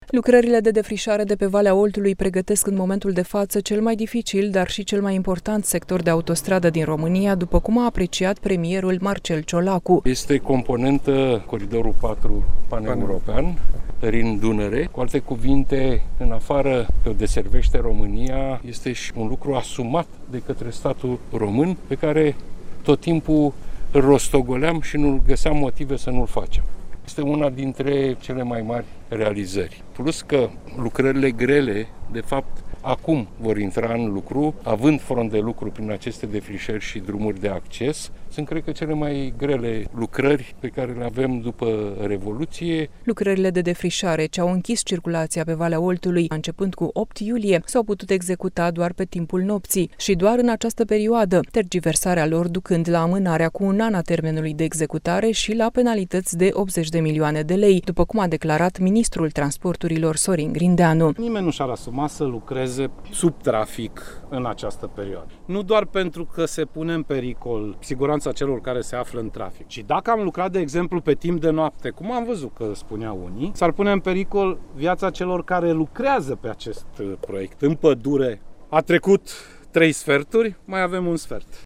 Traficul pe Valea Oltului va fi reluat pe 9 august – spune ministrul Transporturilor, Sorin Grindeanu